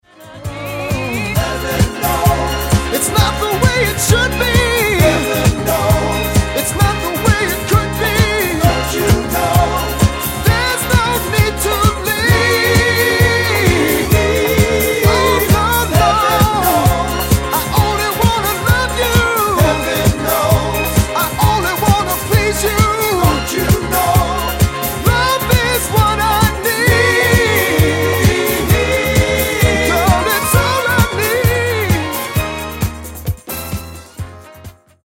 Genere:   Disco Soul